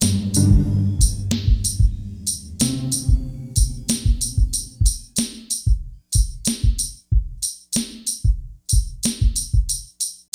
BEAT 6 93 05.wav